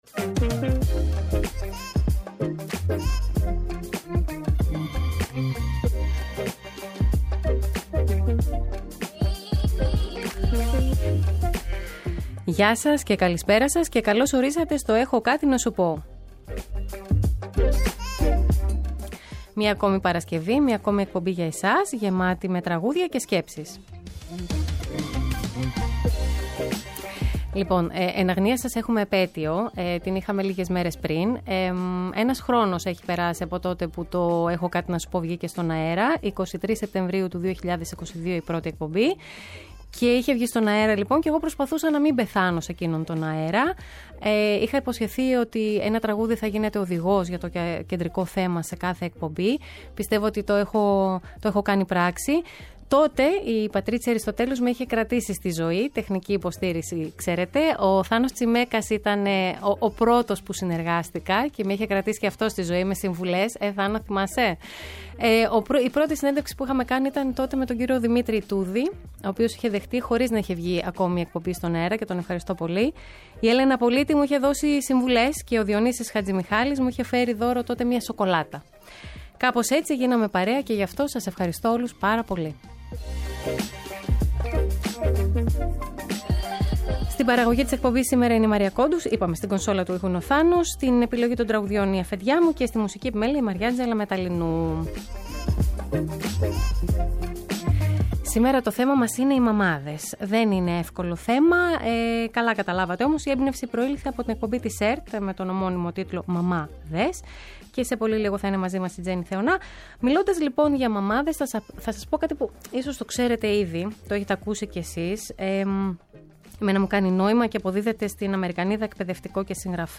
Η αγαπημένη ηθοποιός και παρουσιάστρια συζητεί με τη δημοσιογράφο για τον ρόλο της Πέρσας στη σειρά μυθοπλασίας της ΕΡΤ, «Κάνε ότι κοιμάσαι II», αλλά και για την εκπομπή «Μαμά-δες».Ποια πιστεύει ότι είναι η δύναμη της γονεϊκότητας και πώς μπορεί ένας γονιός να διαχειριστεί τις δικές του προσδοκίες;
Κάθε Παρασκευή 7 με 8 το βράδυ, ένα τραγούδι γίνεται οδηγός για το κεντρικό θέμα σε κάθε εκπομπή. Για το ευ στο ζην, από συναισθήματα και εμπειρίες μέχρι πεποιθήσεις που μας κάνουν να δυσλειτουργούμε ή να κινητοποιούμαστε, έχουν κάτι να μας πουν ειδικοί σε επικοινωνία με ακροατές.